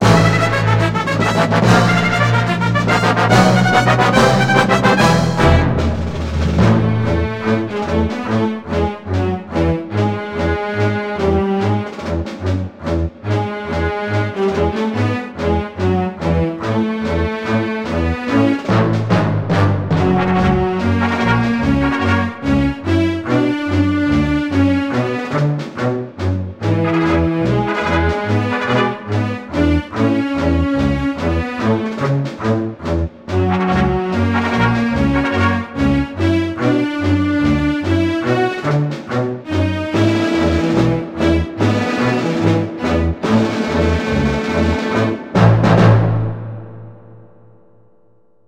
File File history File usage Metadata CBFD-It's_War_cutscene_unused.ogg  (Ogg Vorbis sound file, length 48 s, 167 kbps) This file is an audio rip from a(n) Nintendo 64 game.
CBFD-It's_War_cutscene_unused.ogg.mp3